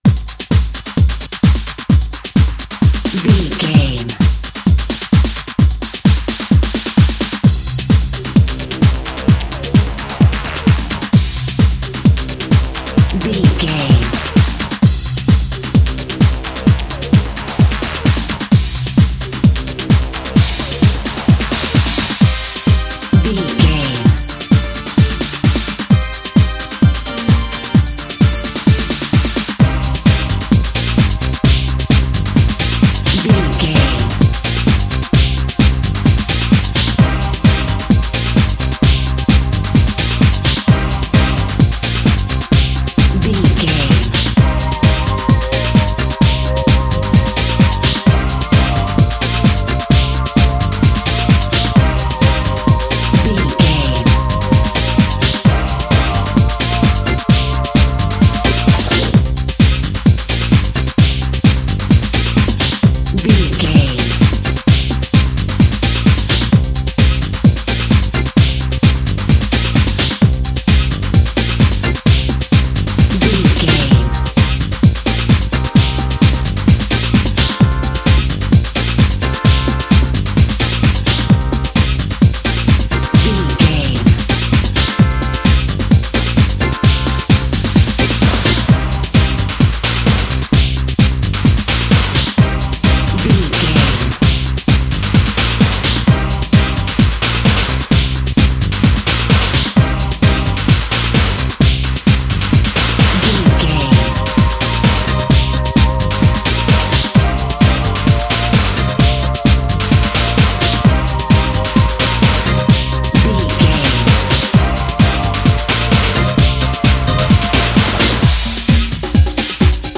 Aeolian/Minor
Fast
drum machine
synthesiser
electric piano
bass guitar
conga
Eurodance